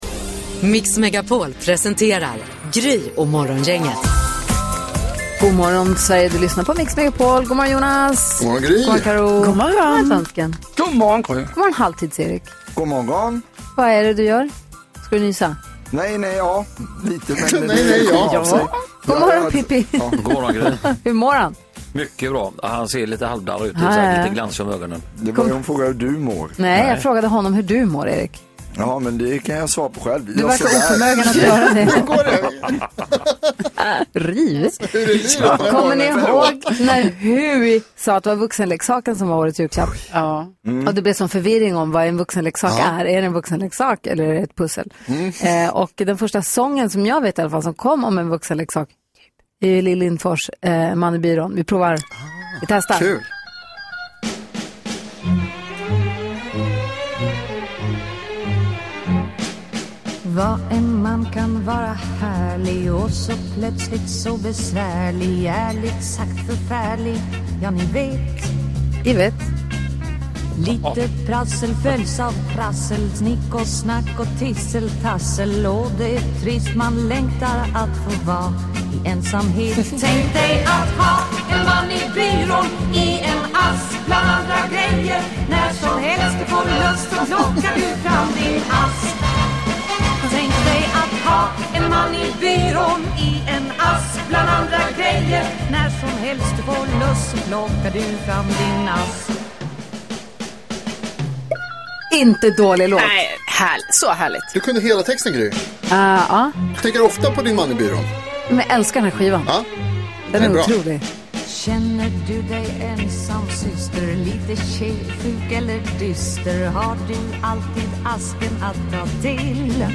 Tvillingduon vi alla älskar hänger med oss i studion i dag.